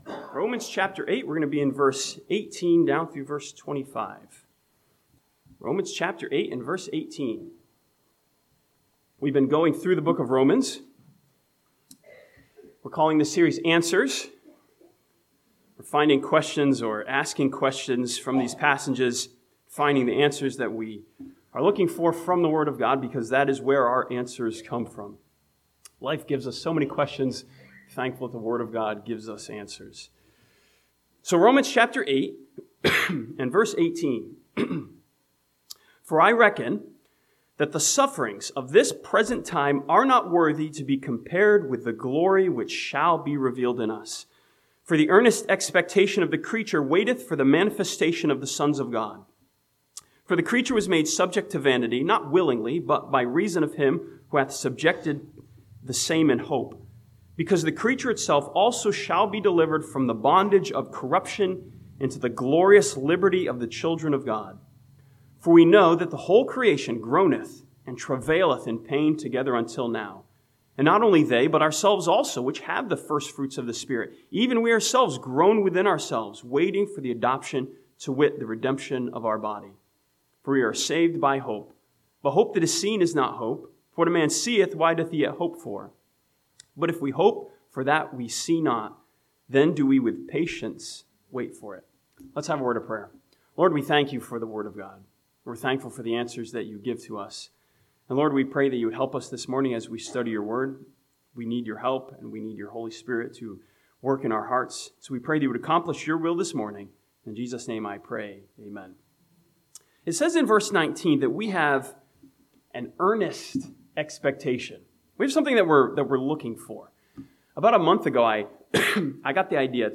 This sermon from Romans chapter 8 studies the earnest expectation of the believer and asks the question, "what are we looking for?"